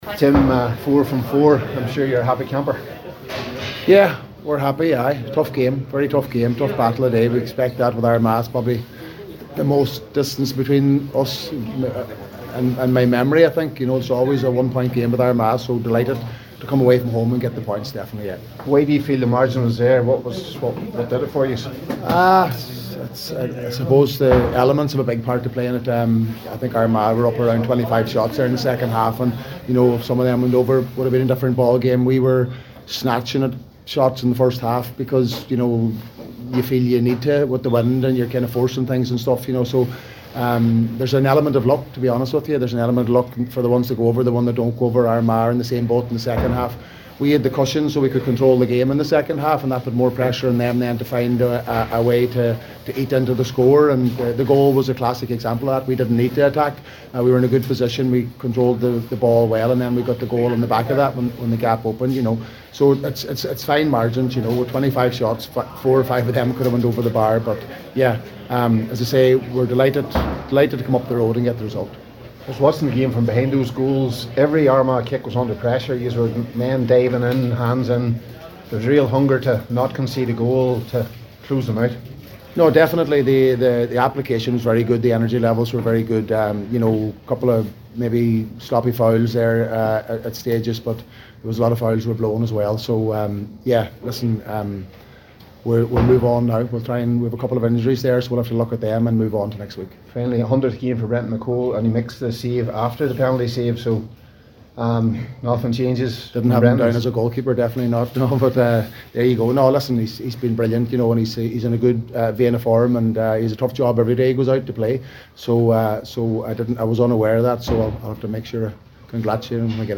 After today’s game, McGuinness spoke to the assembled media and said it was another tough battle with their Ulster rivals…